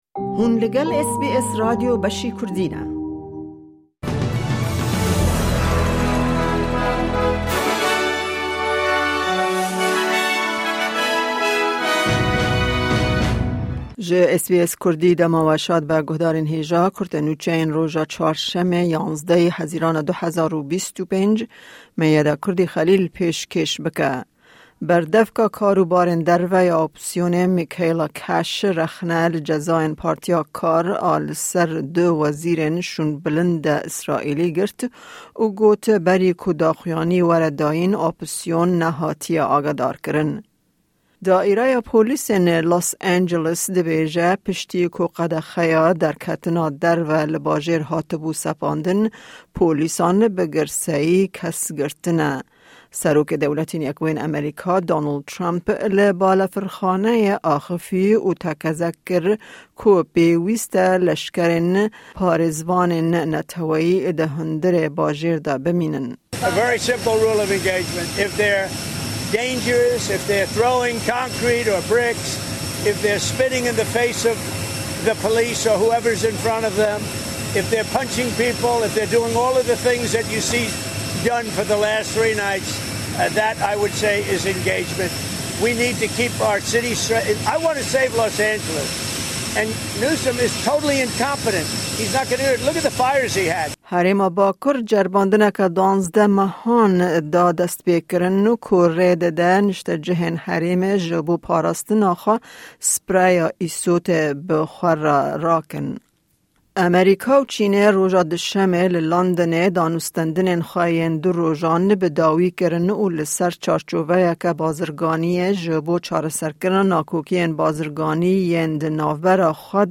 Kurte Nûçeyên roja Çarşemê, 11î Hezîrana 2025